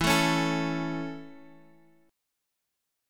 E 6th Suspended 4th